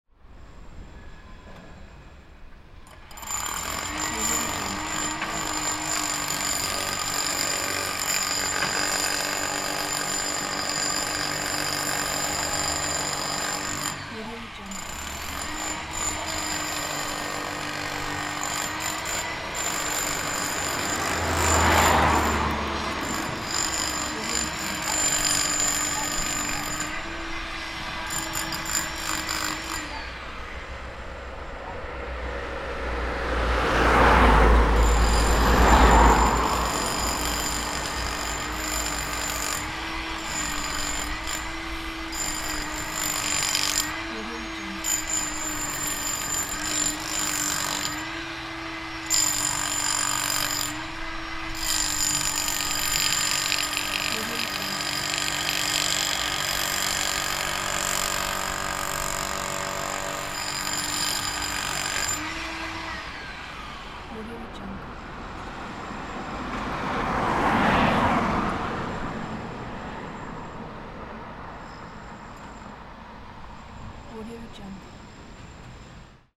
دانلود افکت صوتی صدای پیکور هنگام تخریب
دانلود افکت صوتی شهری